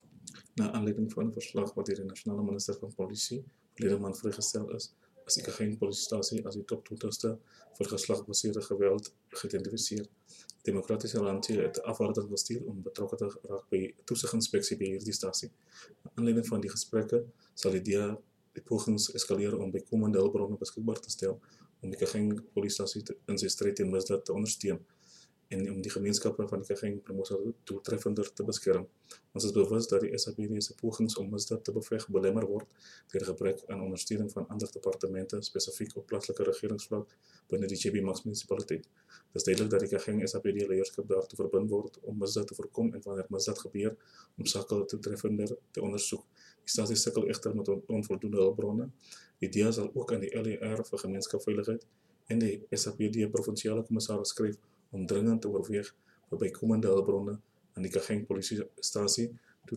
Note to Editors: Please find the attached soundbites in
Afrikaans from the DA Councillor, Glenville Fransman.